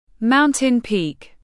Mountain peak /ˌmaʊn.tɪn piːk/
Mountain-peak.mp3